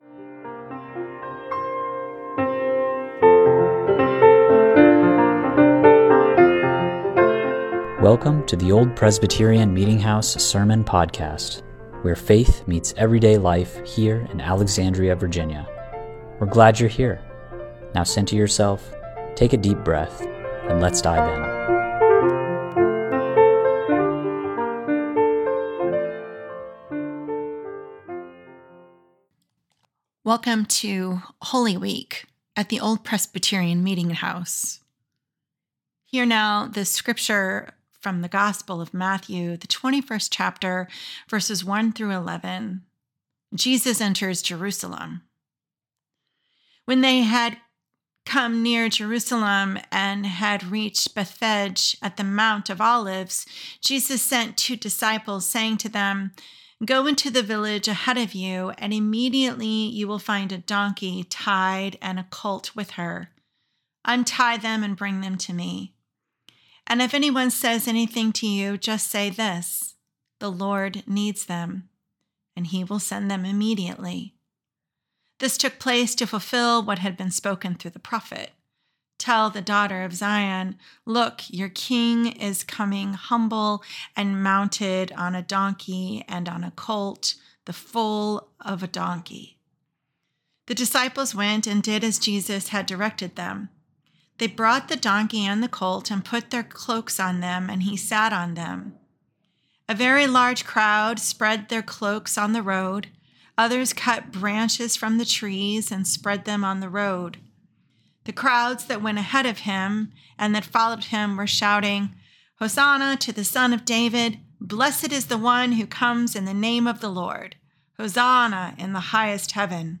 OPMH Sermon Podcast